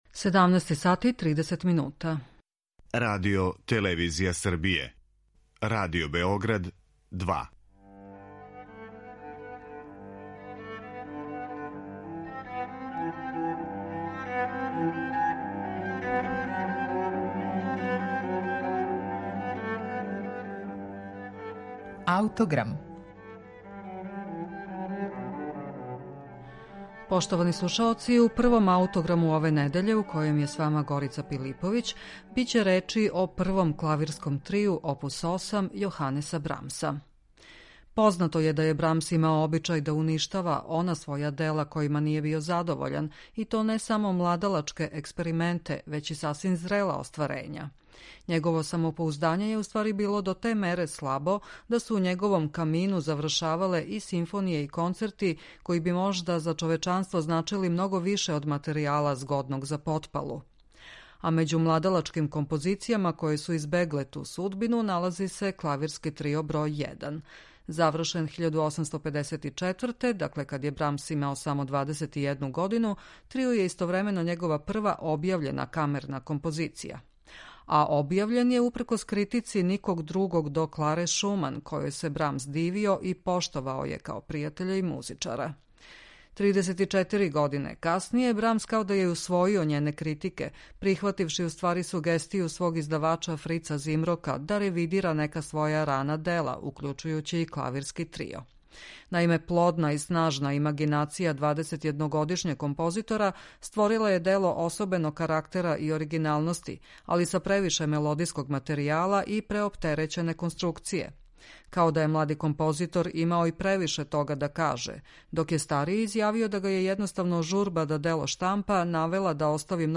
Први клавирски трио Јоханеса Брамса